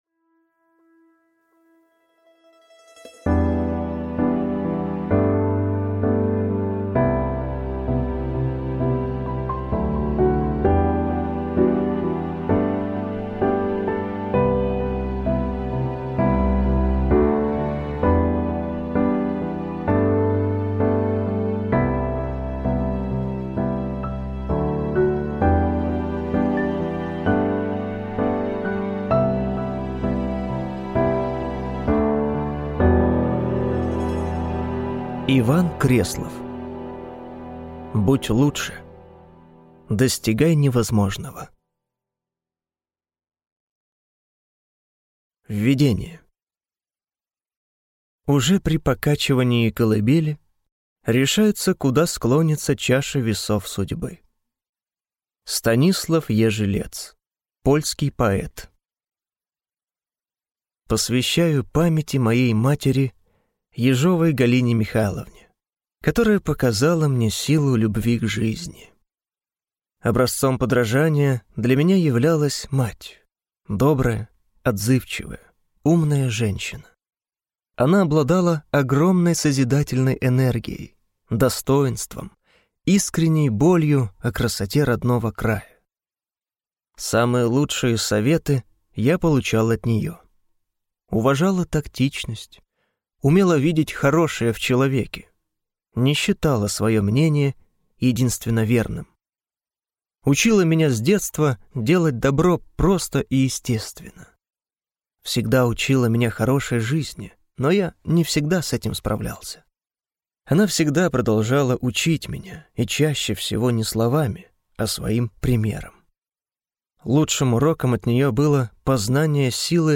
Аудиокнига Будь лучше, достигай невозможного | Библиотека аудиокниг